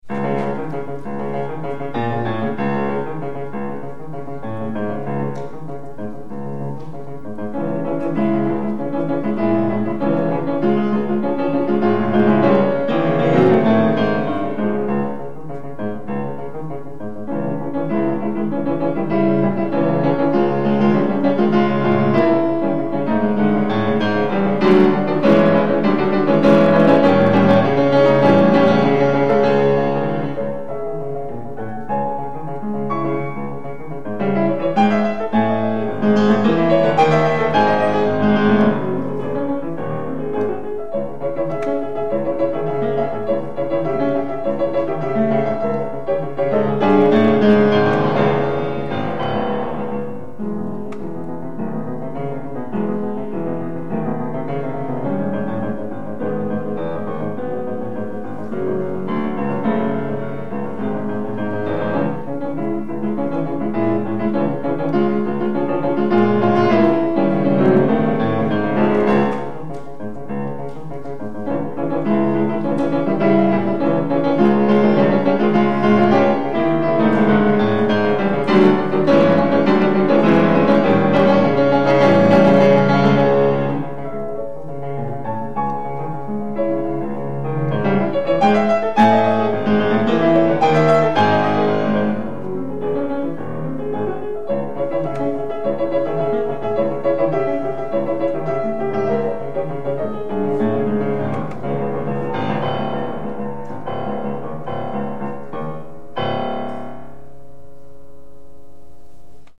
für Klavier
Hochschule für Musik Augsburg 2003